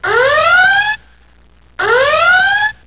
alert.wav